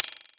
Destroy - WoodsPerc.wav